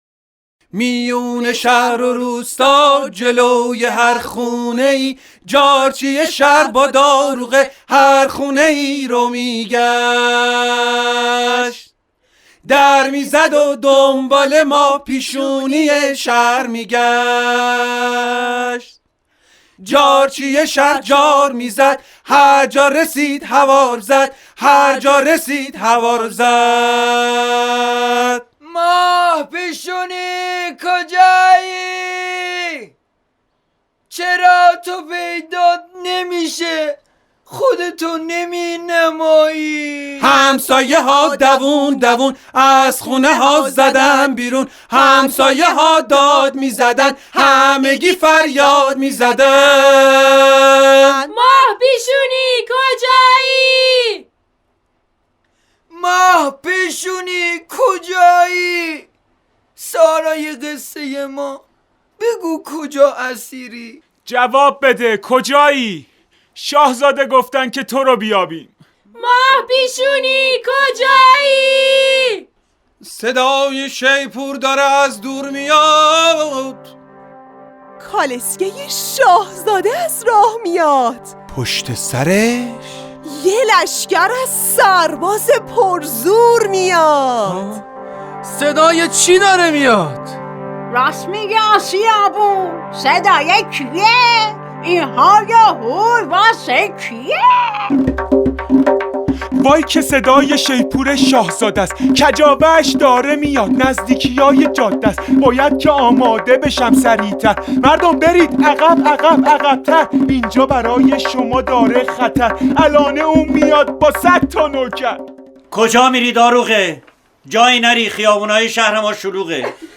نمایش شنیداری و موسیقیایی